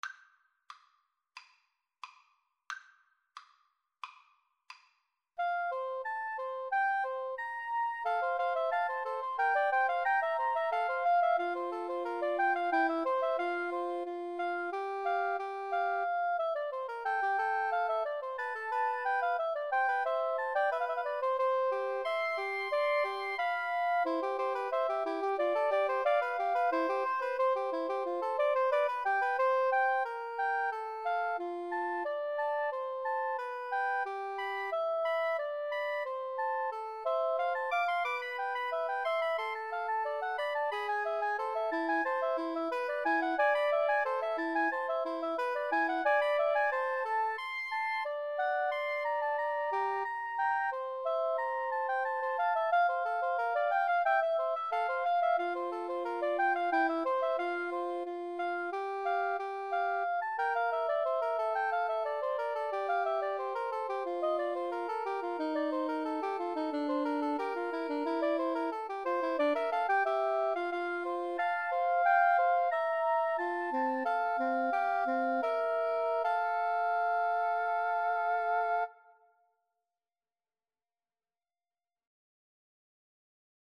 Play (or use space bar on your keyboard) Pause Music Playalong - Player 1 Accompaniment Playalong - Player 3 Accompaniment reset tempo print settings full screen
F major (Sounding Pitch) (View more F major Music for Woodwind Trio )
Woodwind Trio  (View more Advanced Woodwind Trio Music)
Classical (View more Classical Woodwind Trio Music)